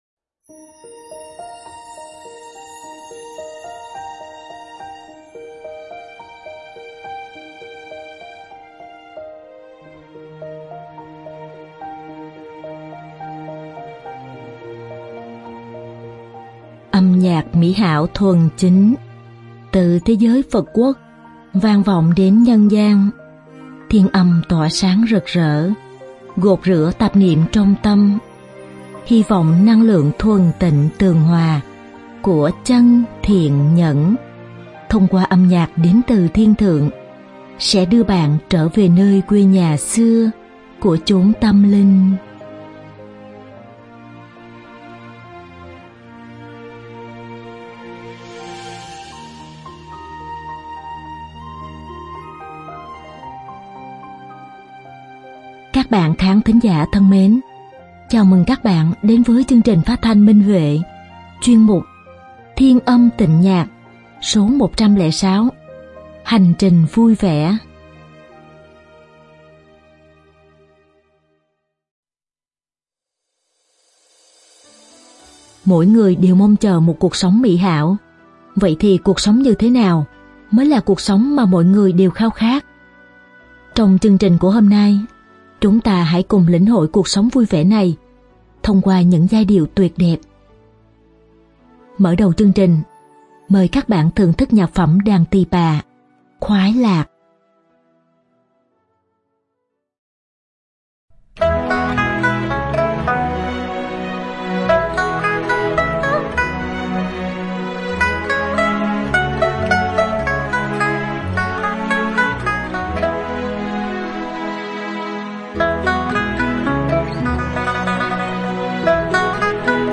Nhạc phẩm đàn tỳ bà